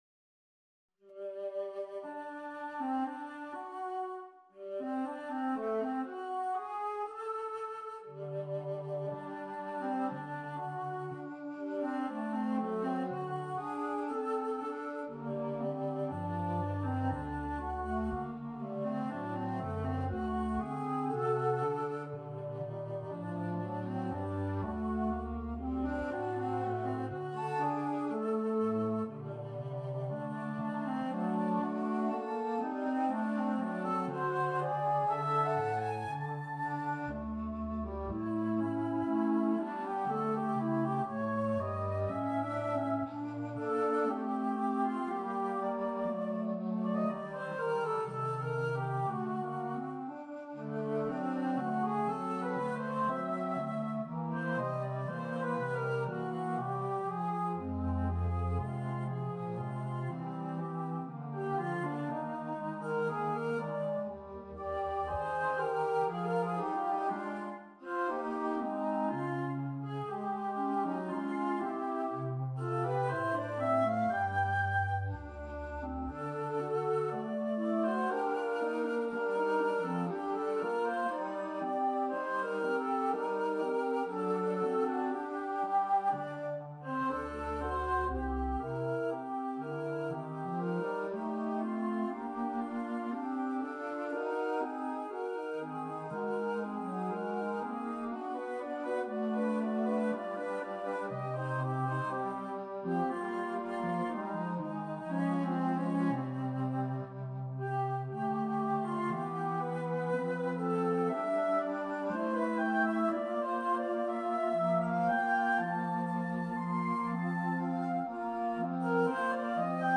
alto flute, bass flute, contrabass flute (2018)
Another pensive trio for low flutes. Performance + no recording = midi.